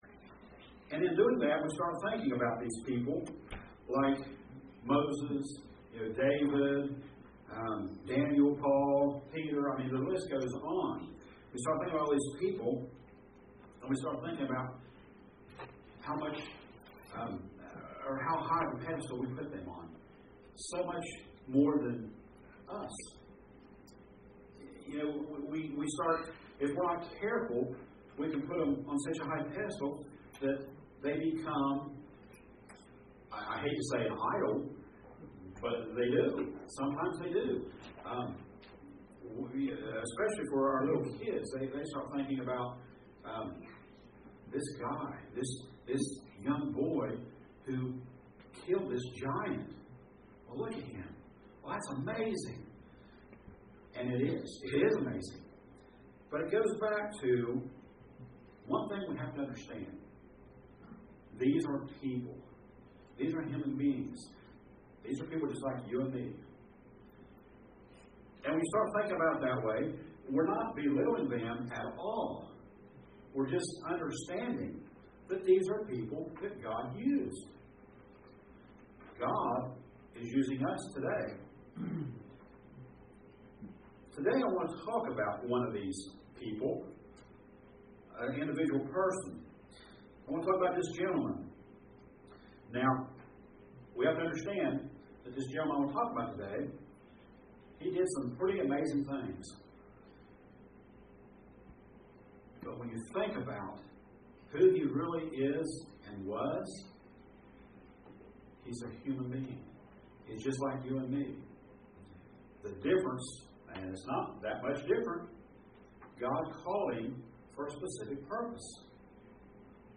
All these questions are answered in this sermon and more....